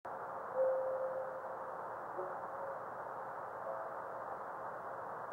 Observer's Comments:  Small fireball. Minimal radio reflection.
No obvious radio reflection with this meteor during the 1157 UT minute.